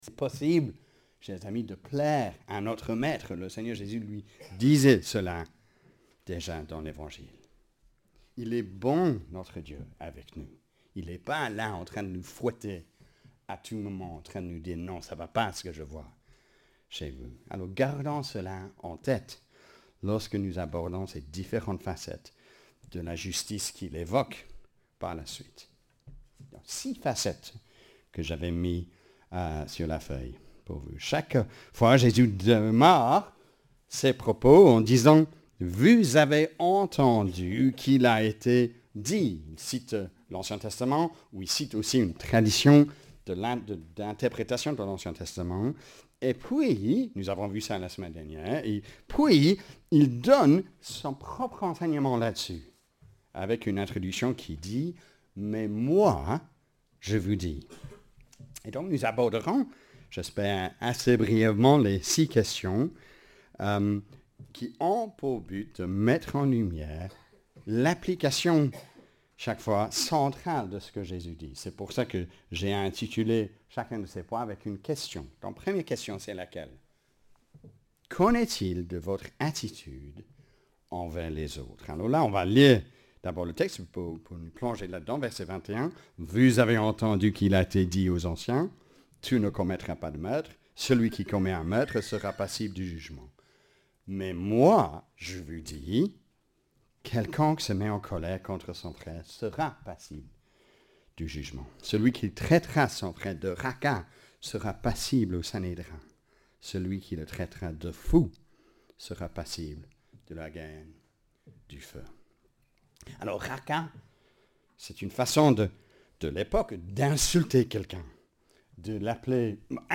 PREDICATION-JUNE-23TH.mp3